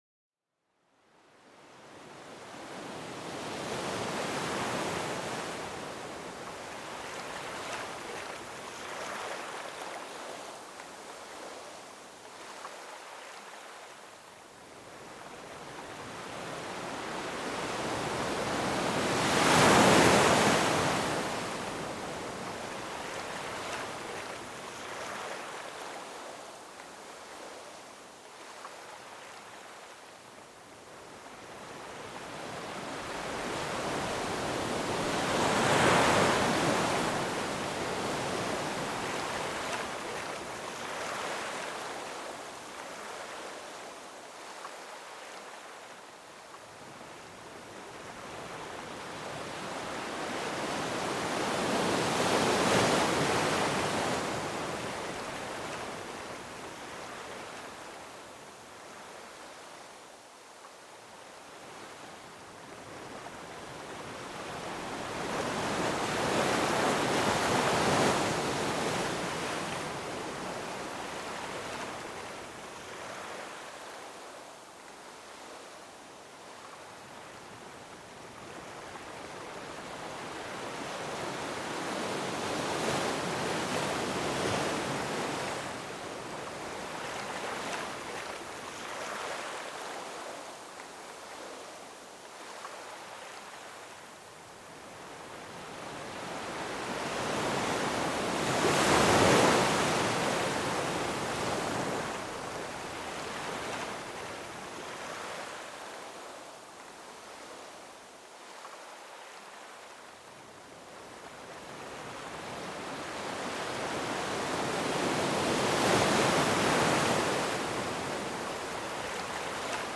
Son apaisant des vagues pour se détendre et améliorer le sommeil
Laissez-vous bercer par le doux va-et-vient des vagues pour un moment de relaxation profonde. Ce son naturel crée une ambiance calme et apaisante pour vous aider à vous endormir.